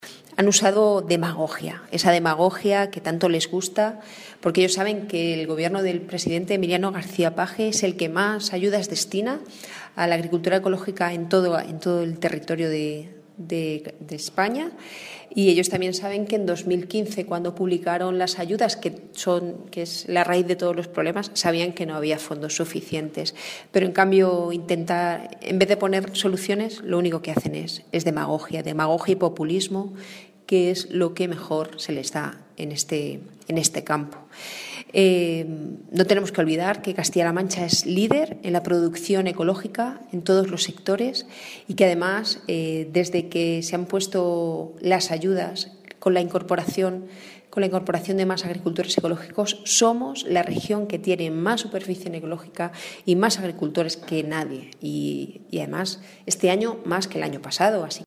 La diputada del Grupo Parlamentario Socialista en las Cortes de Castilla-La Mancha, Rosario García, se ha mostrado "muy satisfecha" con los datos ofrecidos esta semana por el Gobierno regional sobre el aumento de los fondos para la agricultura ecológica para los próximos ejercicios.
Cortes de audio de la rueda de prensa